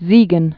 (zēgən)